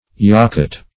Yakut \Ya*kut"\, n.